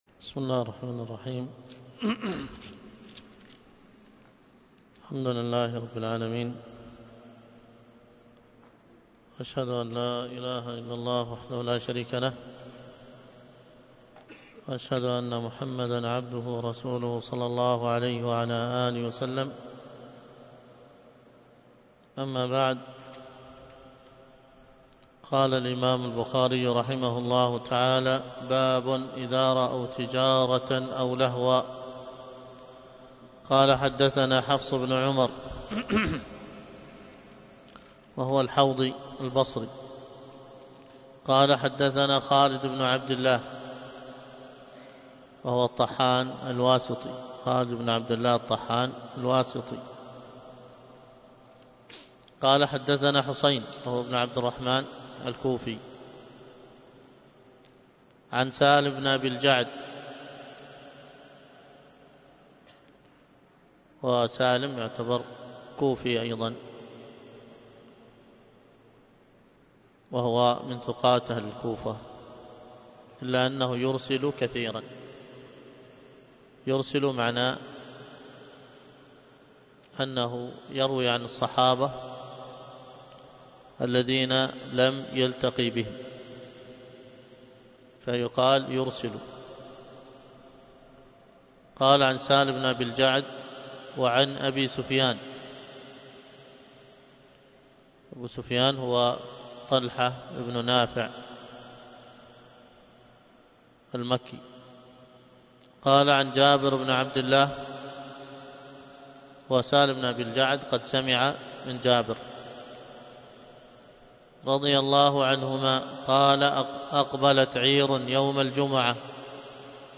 الدرس في كتاب التفسير من صحيح البخاري 233